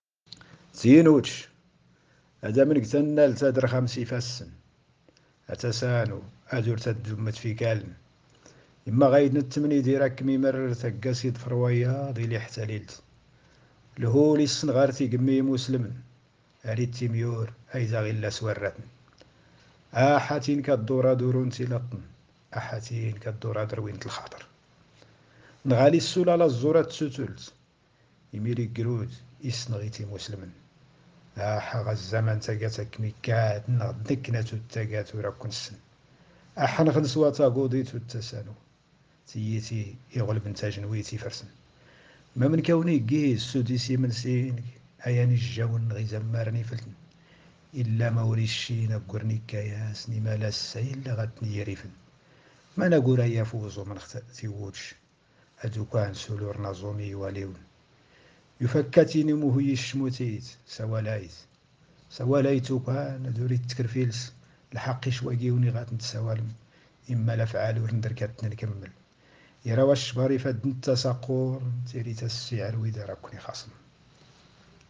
تامديازت/شعر